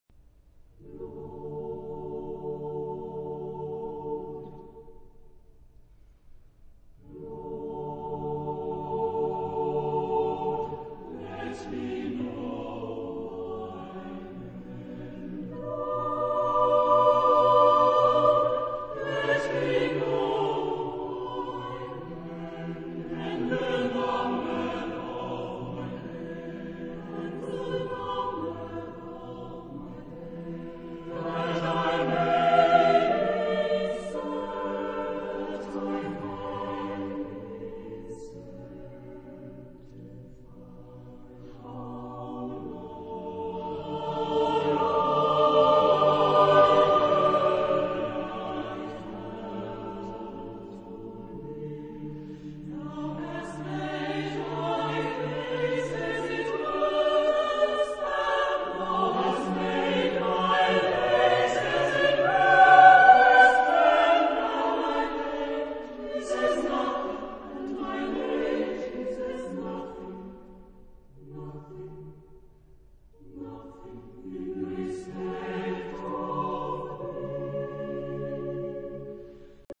Genre-Style-Form: Motet ; Partsong ; Romantic ; Sacred
Mood of the piece: slow
Type of Choir: SATB + SATB  (8 double choir voices )
sung by Ensemberlino
Discographic ref. : 7. Deutscher Chorwettbewerb 2006 Kiel